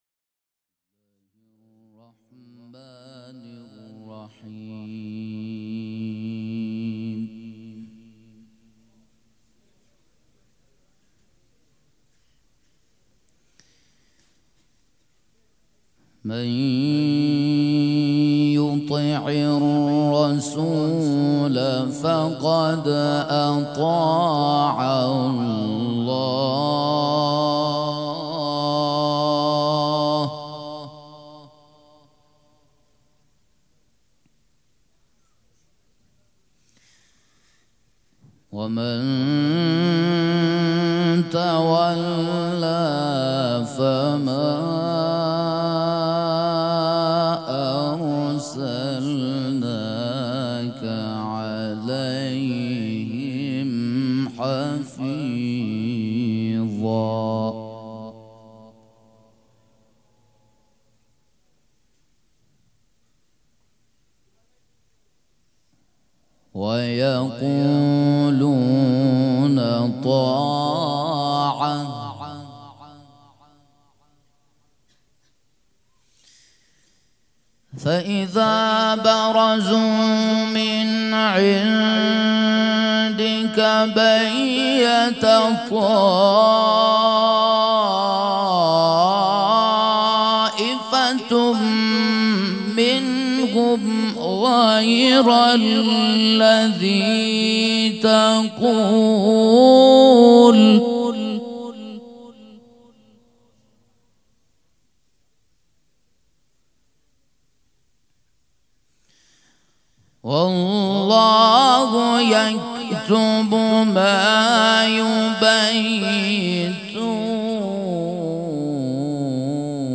قرائت قرآن
قرائت قرآن کریم
مراسم عزاداری شب شهادت حضرت زهرا (س)